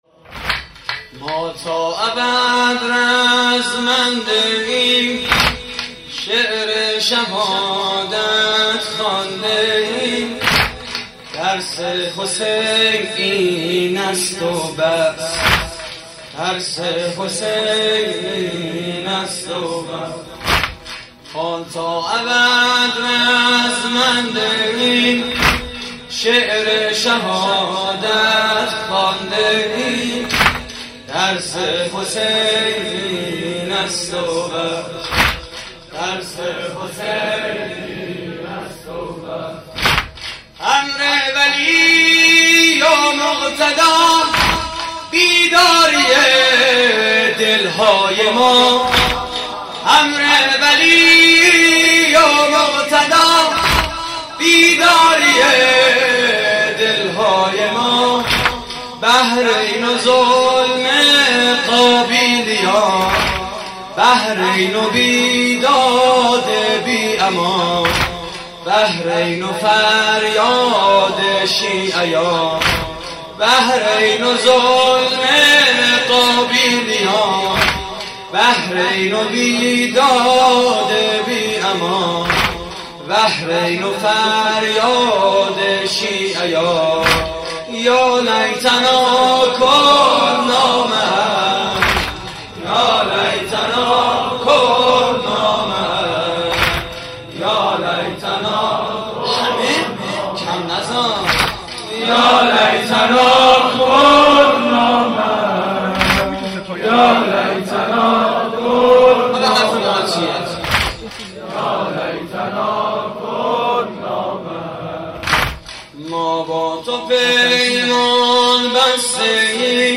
واحد: ما تا ابد رزمنده‌ایم
مراسم عزاداری شب عاشورای حسینی (محرم 1432)